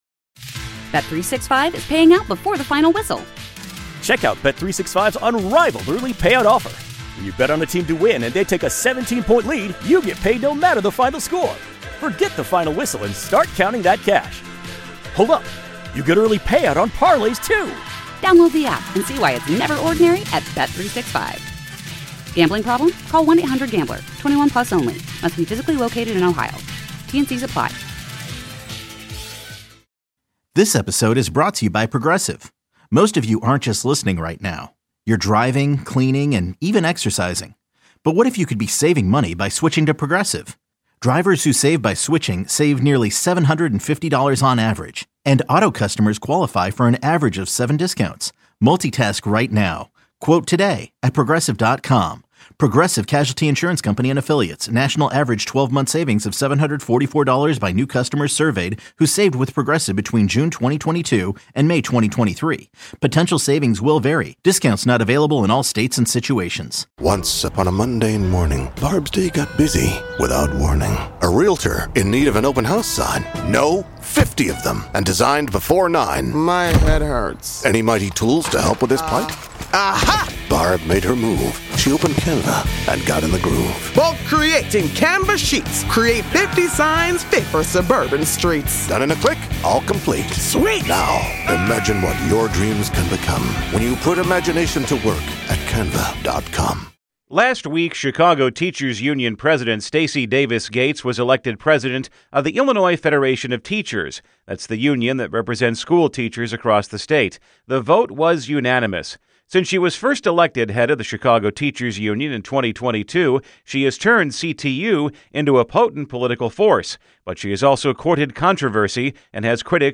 A Discussion on Teaching & Schooling